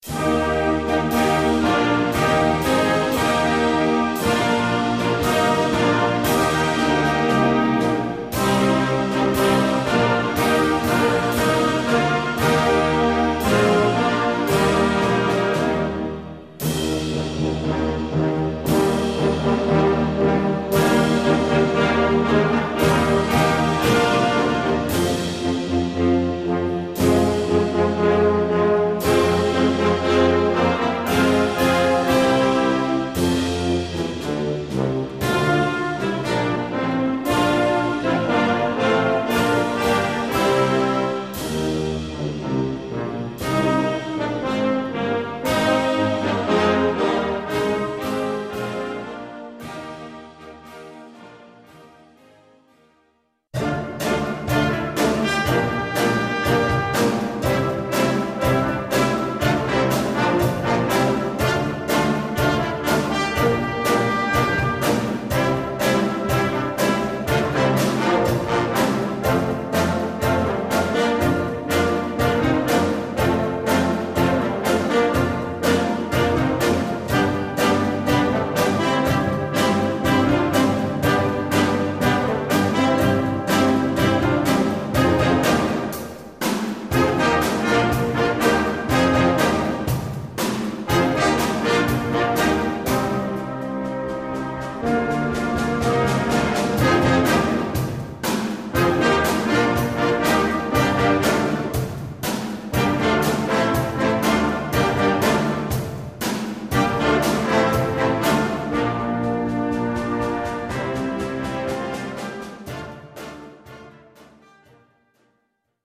4 Flûtes à Bec